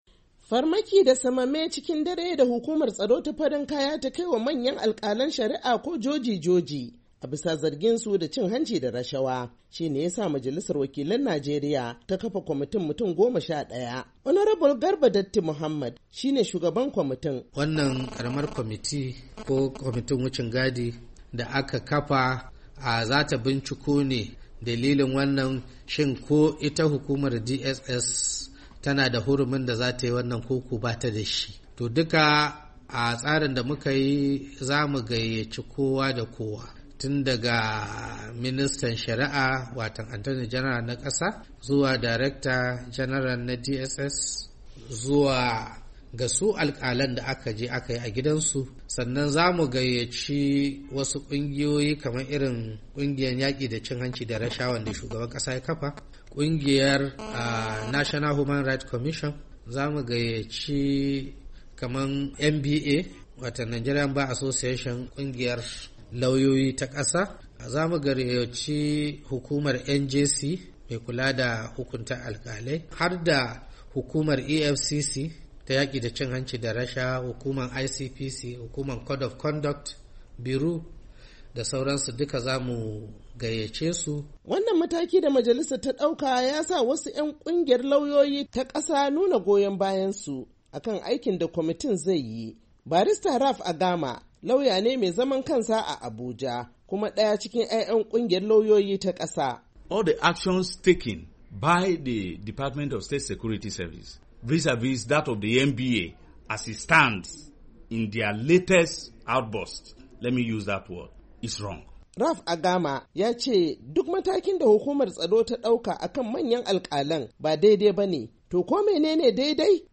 Saurari rahotan